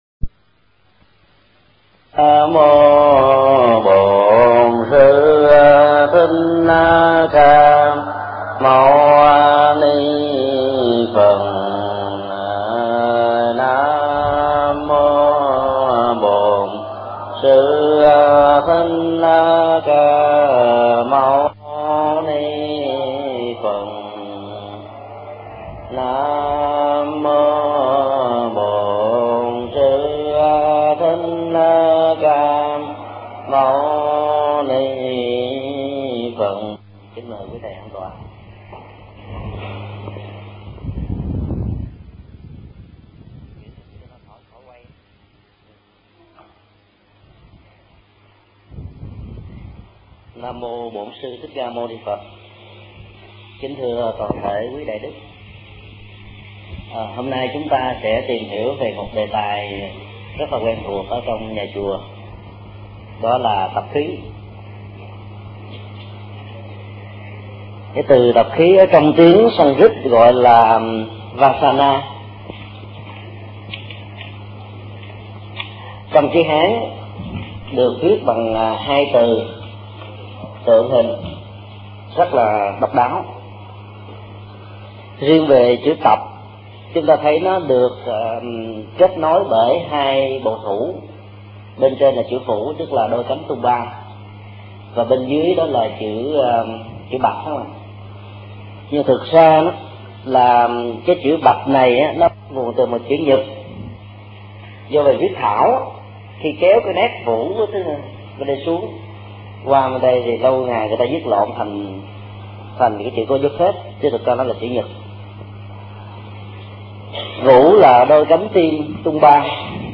Mp3 Pháp Thoại Loại hình thói quen – Thầy Thích Nhật Từ Giảng tại trường hạ chùa Phổ Quang, chùa Hưng Phước, chùa Phổ Đà, chùa Giác Nguyên, Tịnh xá Trung Tâm 2004